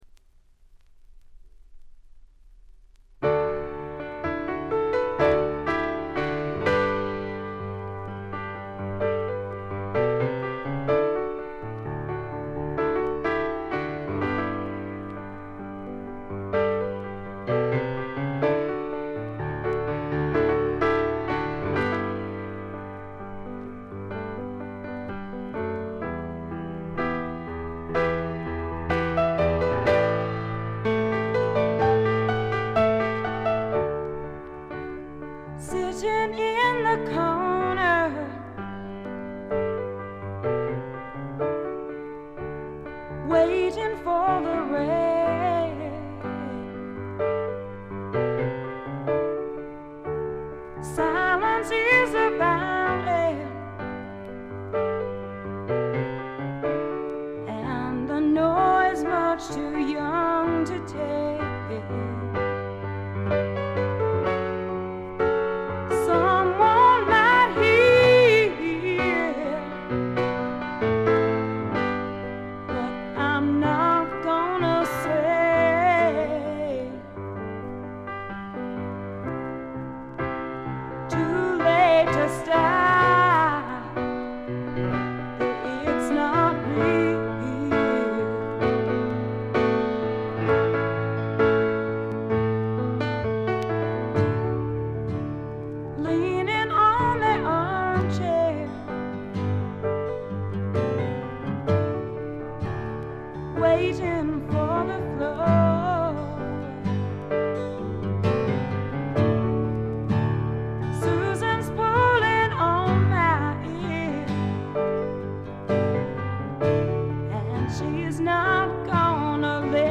少しコケティッシュで、ソウルフルで、実に魅力的なヴォイスの持ち主。女性スワンプの大傑作です。
試聴曲は現品からの取り込み音源です。